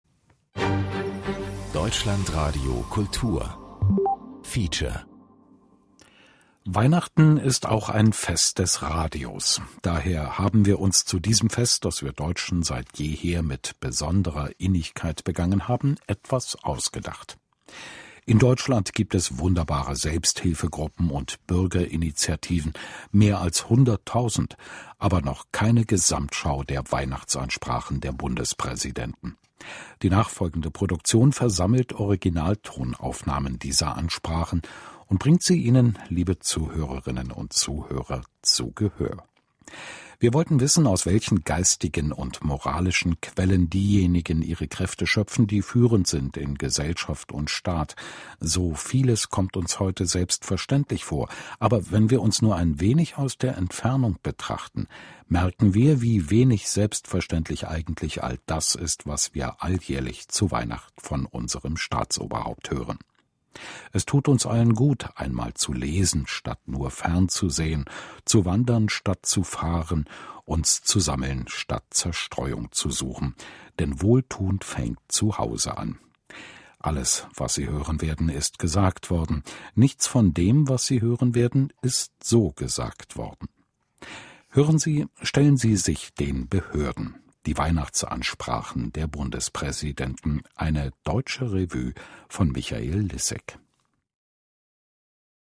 * . zwei h�bsche anmoderationen deutschlandradiokultur swr2 . footage-material interview zur sendung, swr2 interview zur sendung, swr4 ...